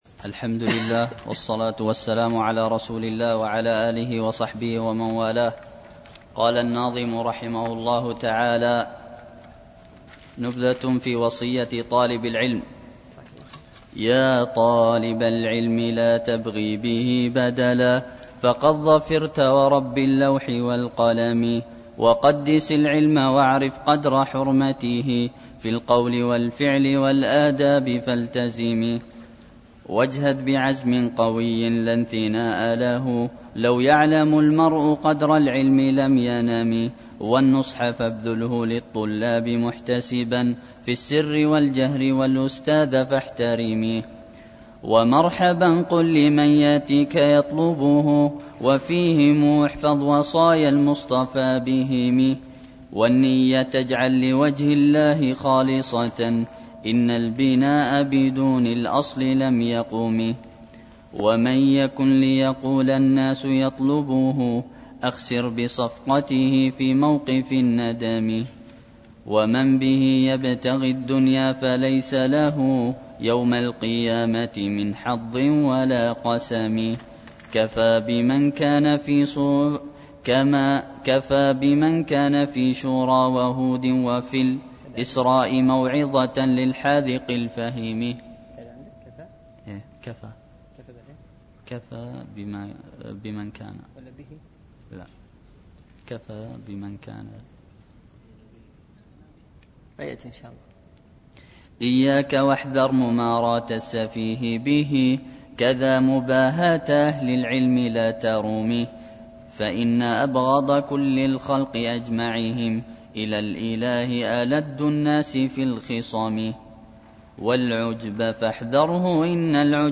شرح المنظومة الميمية في الوصايا والآداب العلمية ـ الدرس الرابع
دروس مسجد عائشة
التنسيق: MP3 Mono 22kHz 32Kbps (CBR)